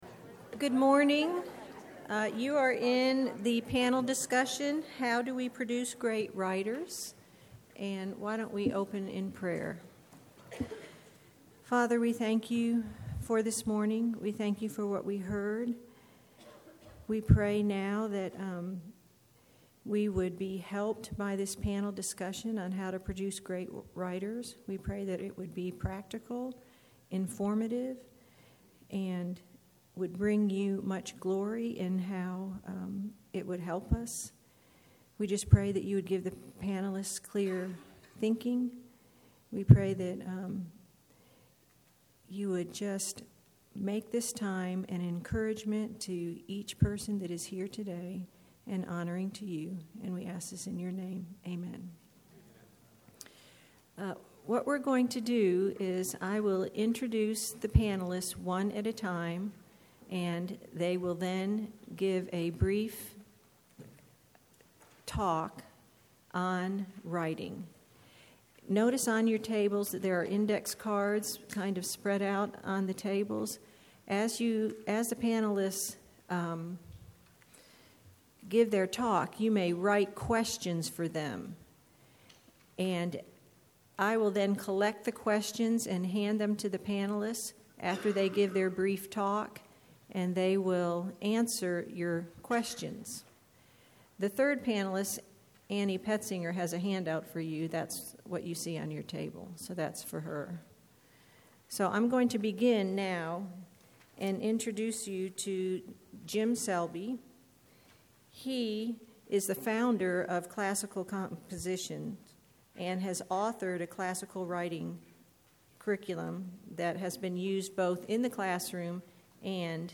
2012 Workshop Talk | 1:00:17 | All Grade Levels, Rhetoric & Composition
Each panelist will make opening remarks. Following these remarks, the panel will answer questions from the audience.
How Do We Produce Great Writers Panel Discussion.mp3